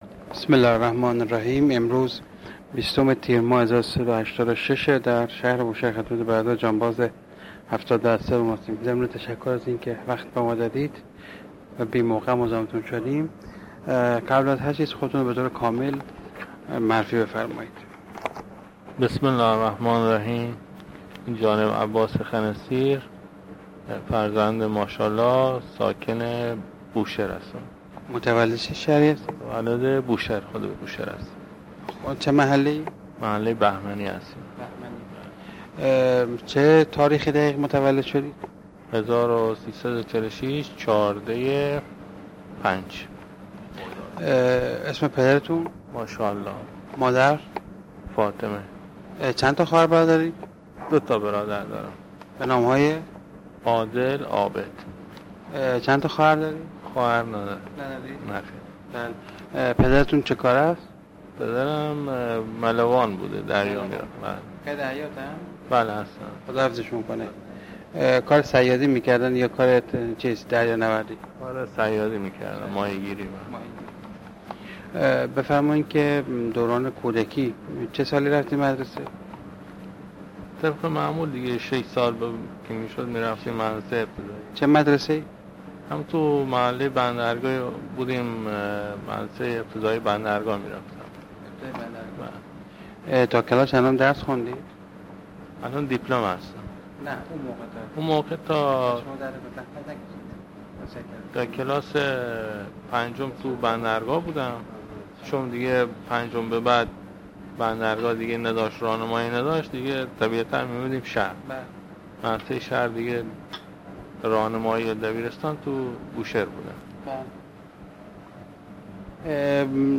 صوت مصاحبه